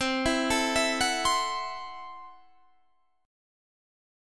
Listen to C6b5 strummed